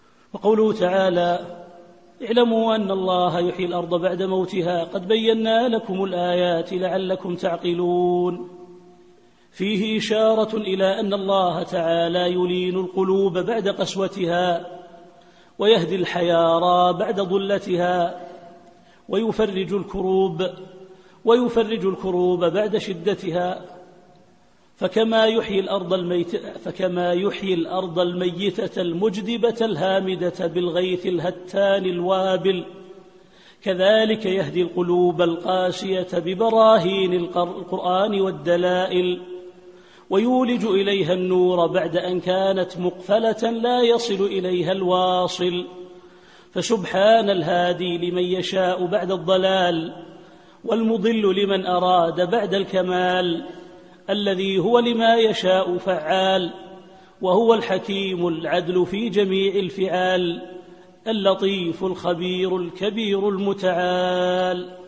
التفسير الصوتي [الحديد / 17]